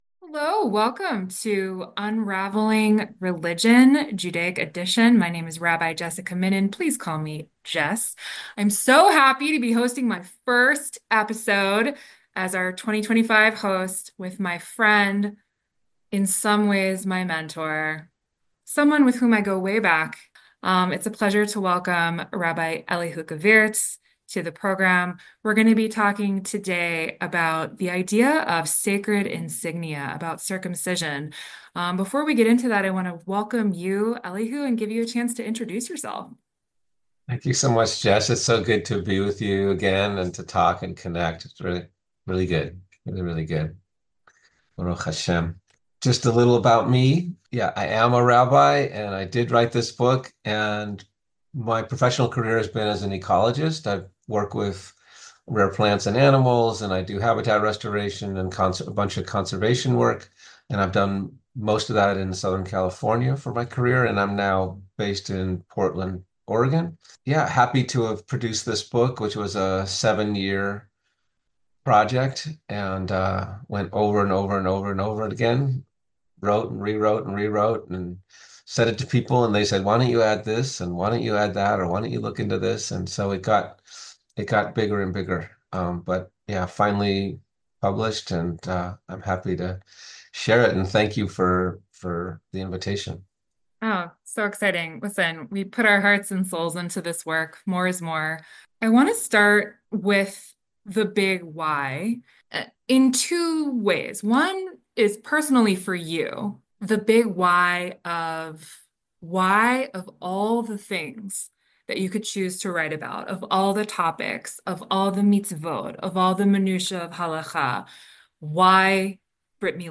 Explorations and Discussions of the Book 'Sacred Insignia: The Spiritual Significance of Brit Milah, Circumcision, and the Sacred Sexual Relationship Between Men and Women'